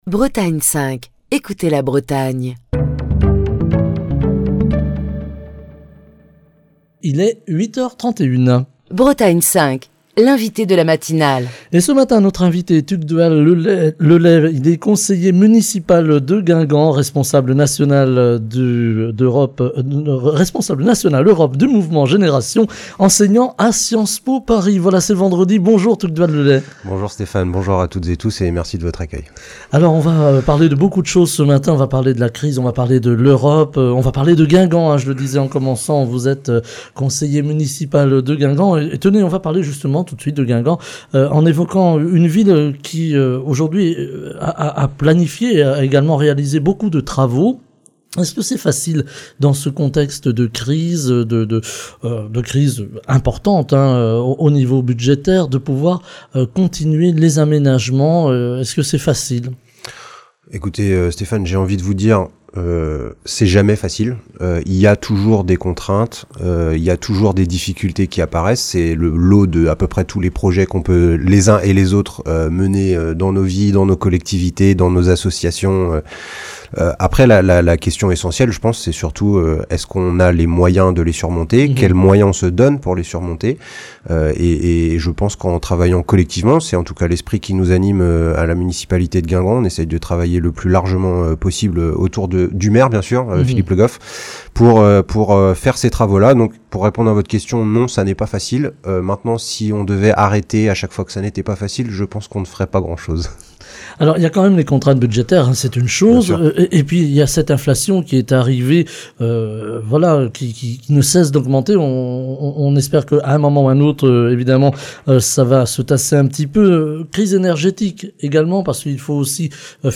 Tugdual Lelay, conseiller municipal de Guingamp, responsable national Europe du Mouvement Génération•s, enseignant Sciences Po Paris est l'invité de Bretagne 5 Matin.